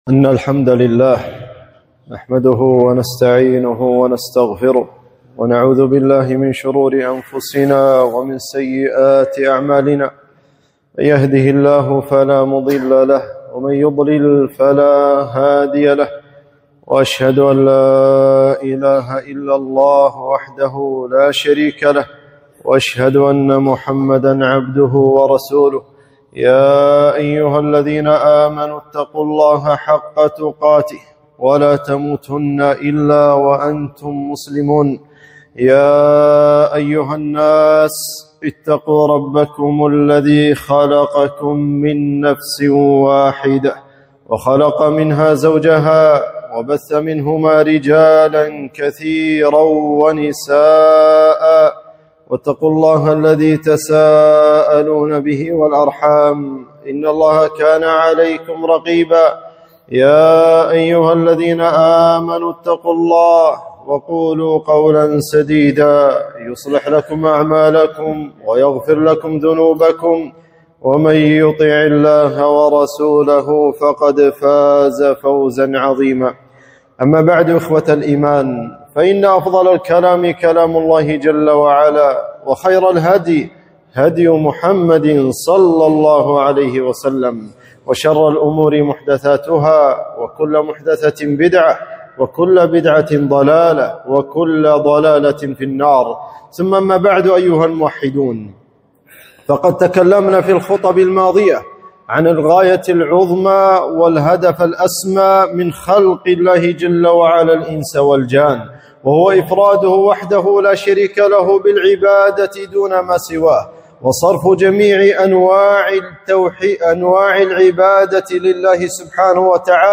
خطبة - الشرك بالله